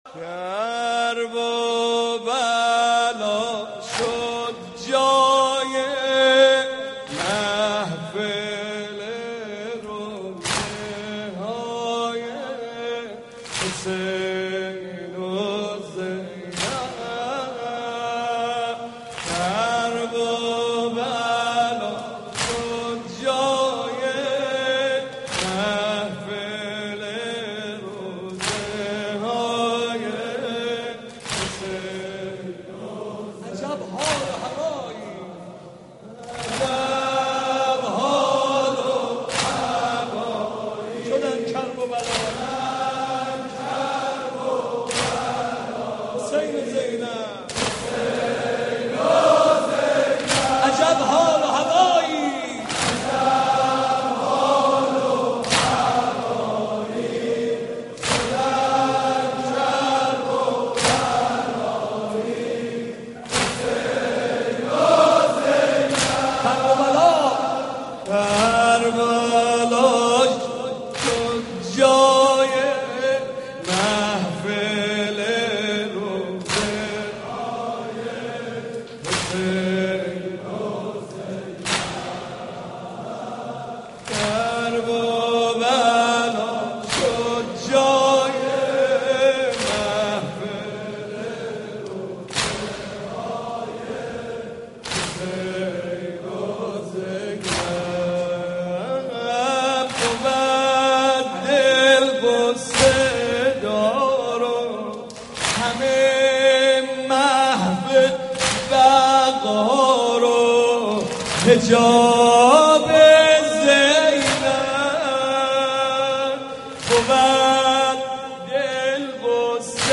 محرم 88 - سینه زنی 1